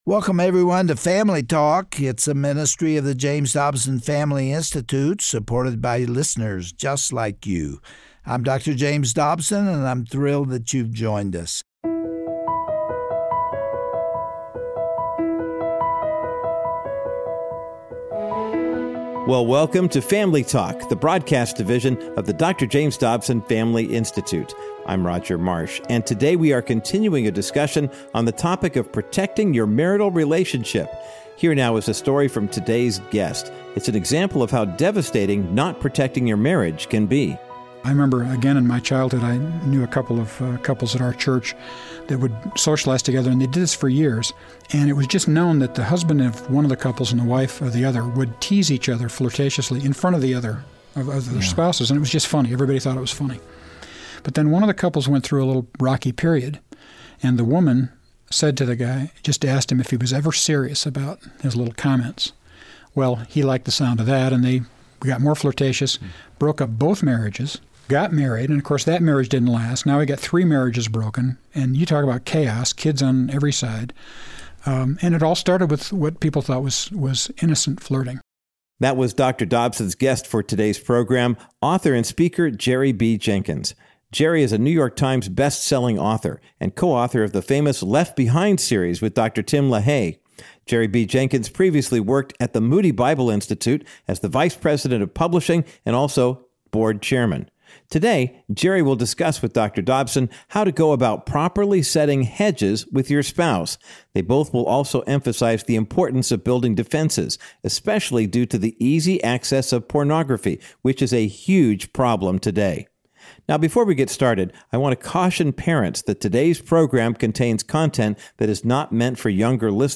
On today’s classic edition of Family Talk, Dr. James Dobson continues his discussion with popular author and speaker, Jerry Jenkins. He identifies specific “hedges” that must be intentionally built into a marital relationship if it is to remain intact, the way God intended.